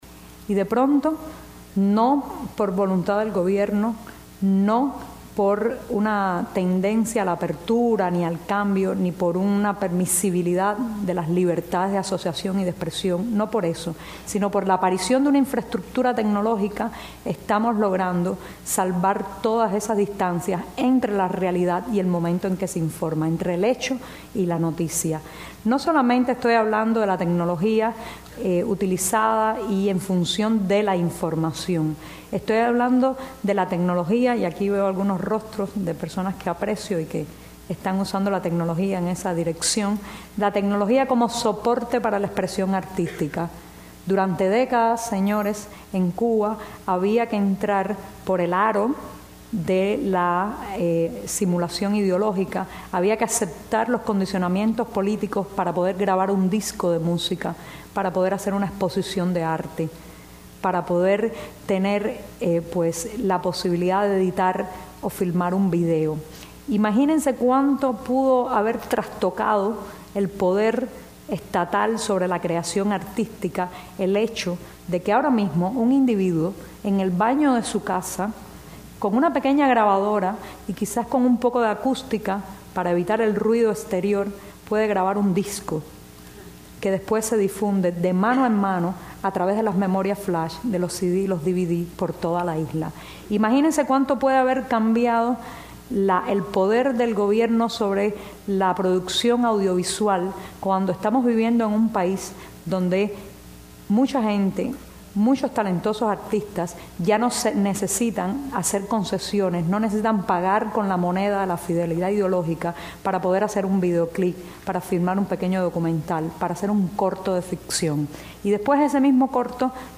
Conferencia de Yoani Sánchez en NYU parte 1